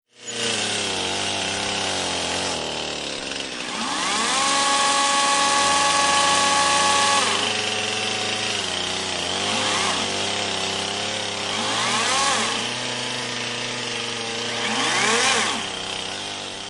Trimming the Yard
SFX
yt_gWBMSP2OIOk_trimming_the_yard.mp3